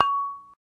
ding.ogg